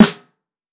Major Snare.wav